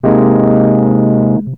02 Rhodes 2.wav